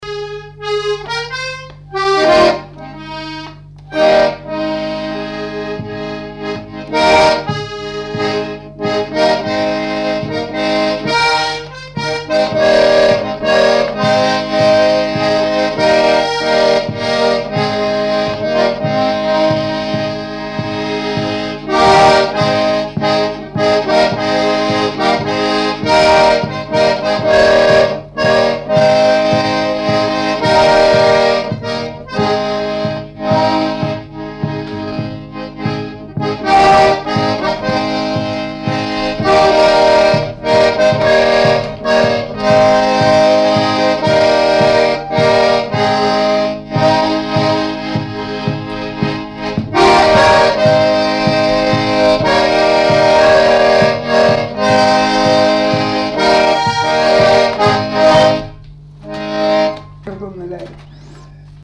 Lieu : Pompiac
Genre : morceau instrumental
Instrument de musique : accordéon diatonique